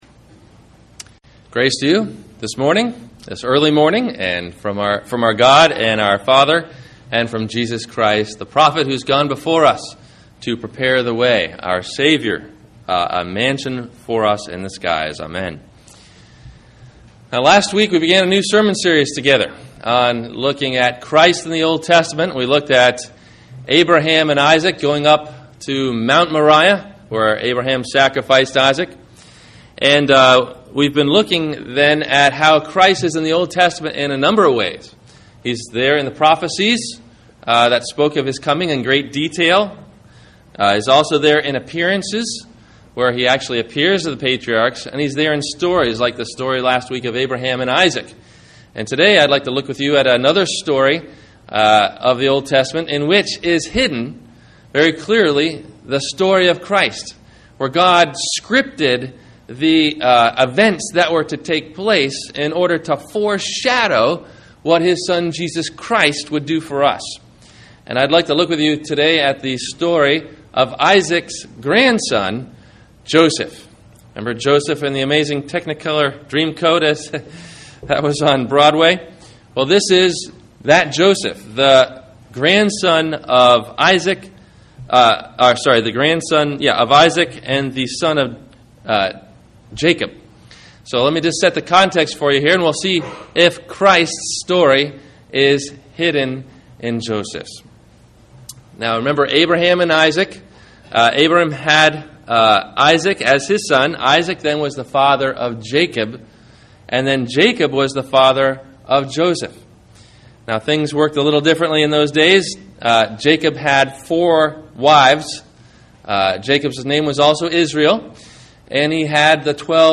The Greater Joseph, (Christ in the OT) – Sermon – March 08 2009